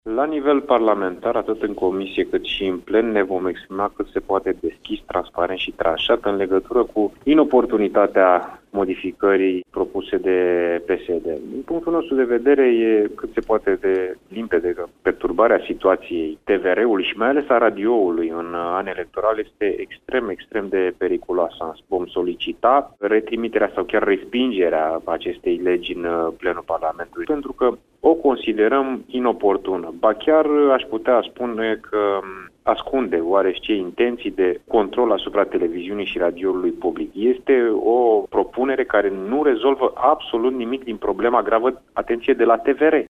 Purtătorul de cuvânt al PNL, deputatul Ionuţ Stroe, a declarat că această iniţiativă nu prezintă soluţii tehnice de redresare a situaţiei de la TVR, ci creează noi funcţii şi impune un mecanism de control editorial şi financiar al celor două instituţii, care funcţionează după aceeaşi lege.